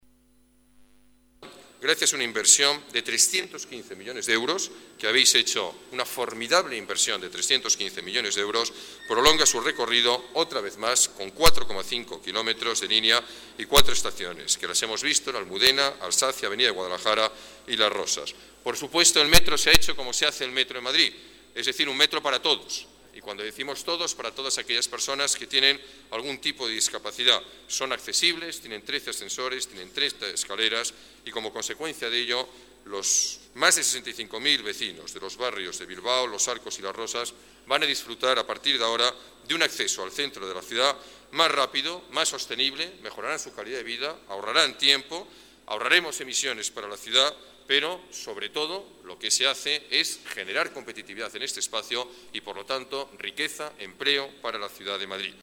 Nueva ventana:Declaraciones alcalde, Alberto Ruiz-Gallardón: ampliación Línea 2 de Metro